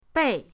bei4.mp3